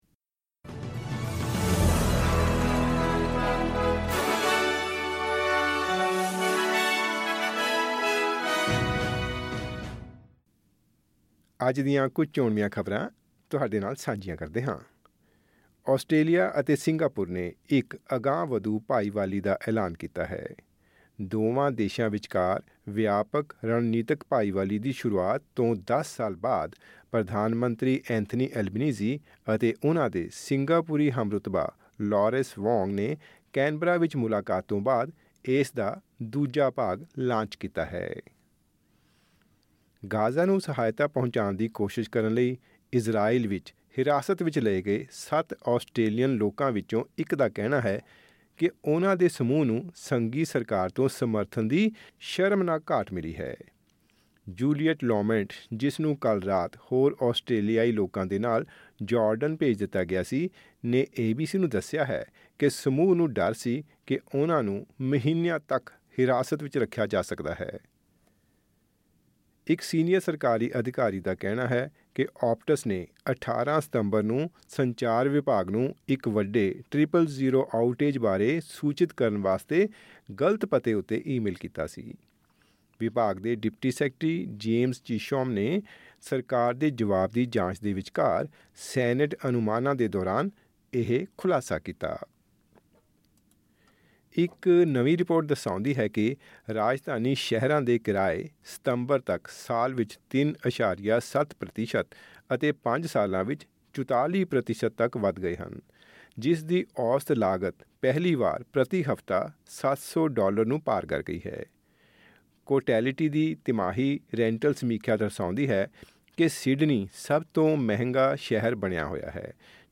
ਖਬਰਨਾਮਾ: ਆਸਟ੍ਰੇਲੀਆ ਵਿੱਚ ਘਰਾਂ ਦੇ ਕਿਰਾਏ ਪਹੁੰਚੇ ਨਵੇਂ ਰਿਕਾਰਡ ਪੱਧਰ 'ਤੇ